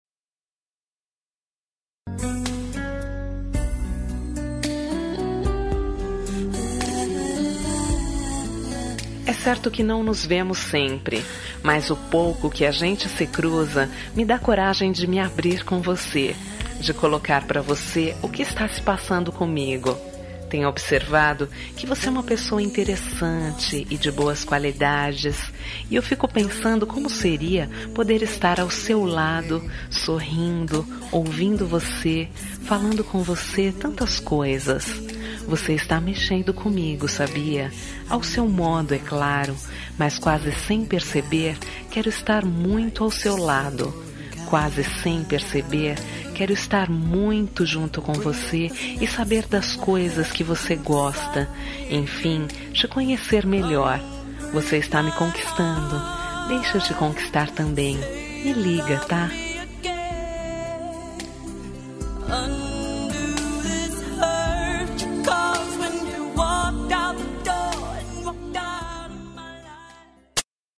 Telemensagem de Paquera – Voz Feminina – Cód: 2157